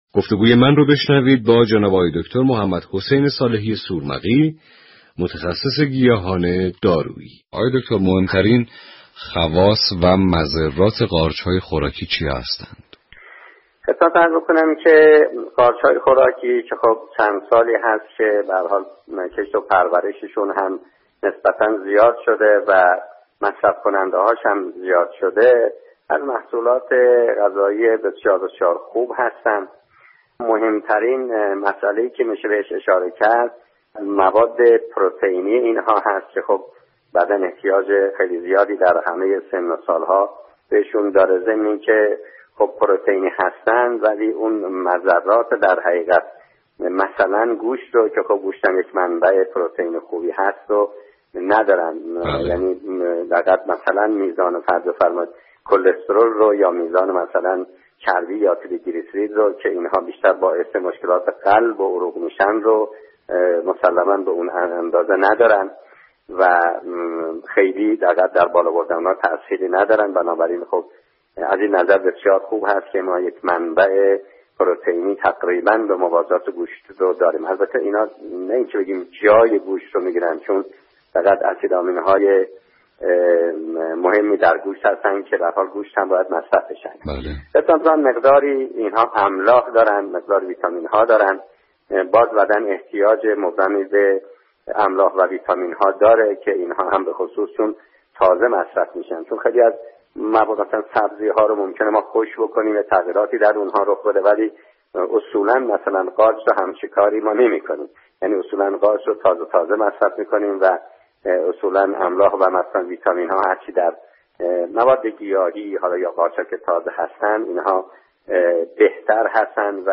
گفتگو
متخصص گیاهان دارویی